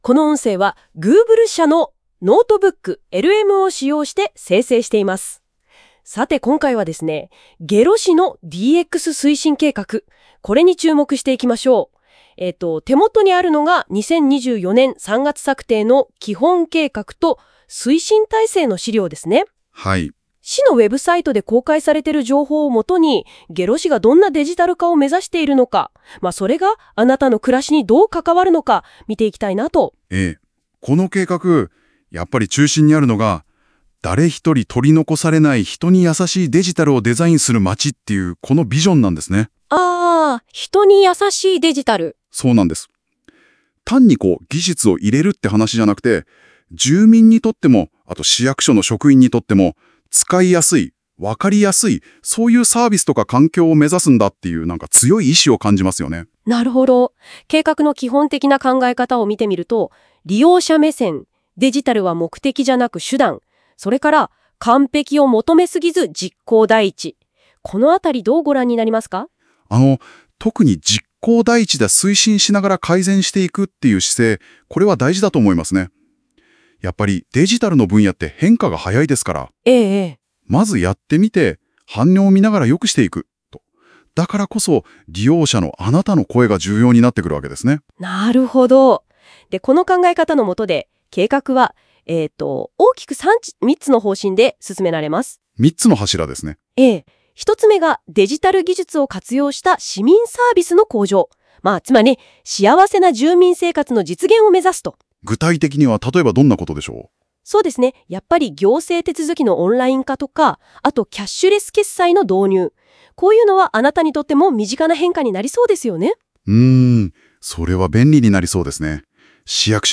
当ページの内容を生成AIによって作成した音声概要を聞くことができます。